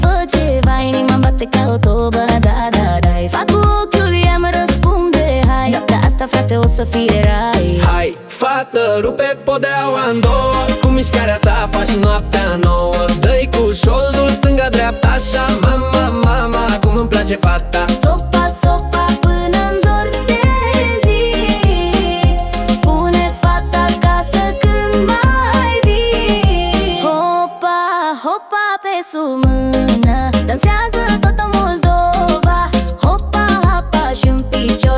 2025-07-01 Жанр: Танцевальные Длительность